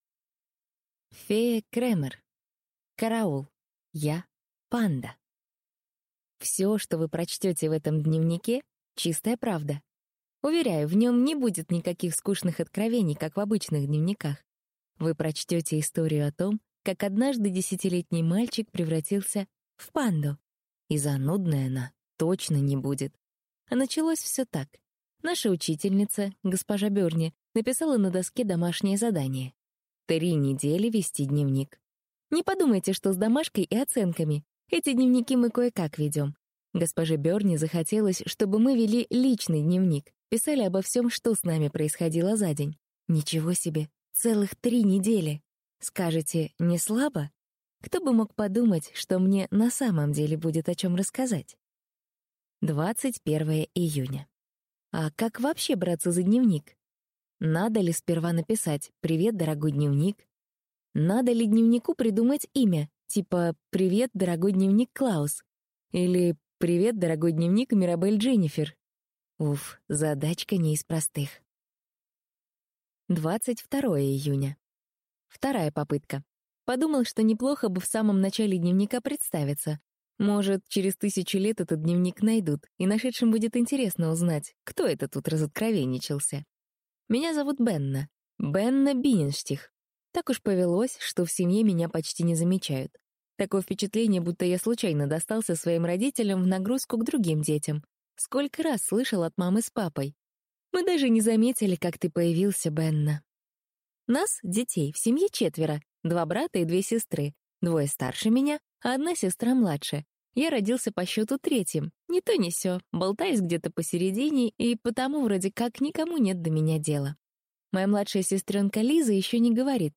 Аудиокнига Караул, я панда!